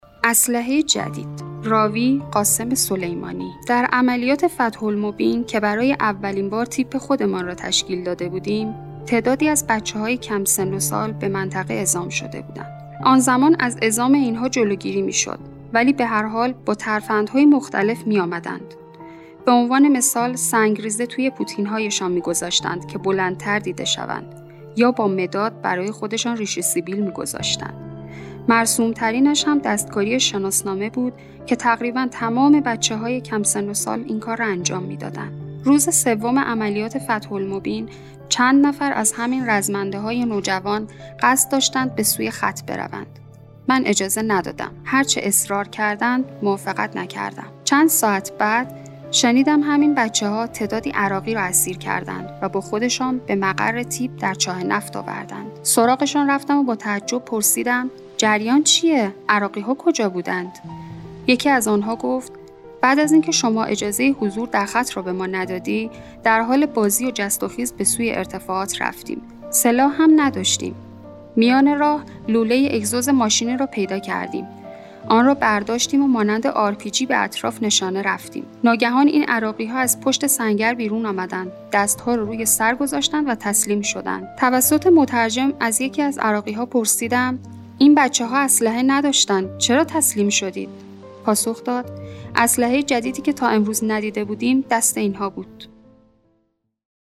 راوی: قاسم سلیمانی